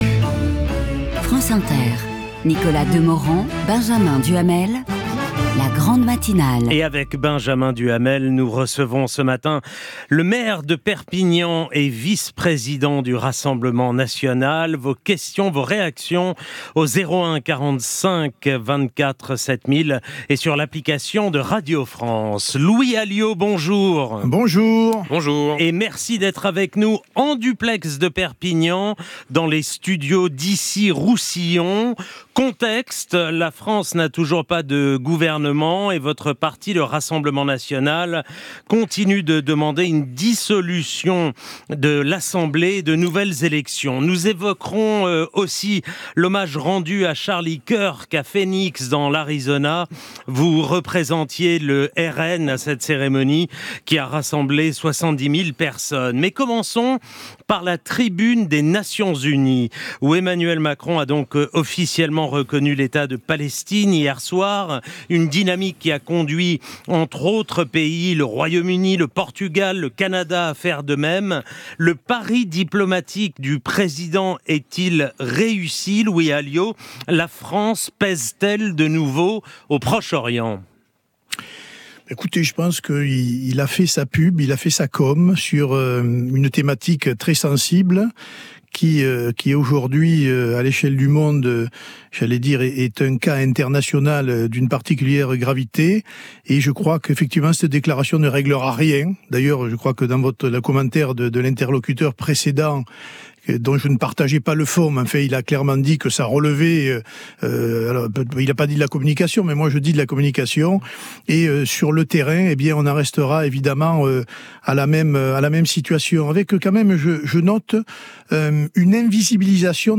Écouter L'invité de 8h20 : le grand entretien en direct